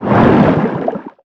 Sfx_creature_hiddencroc_swim_fast_02.ogg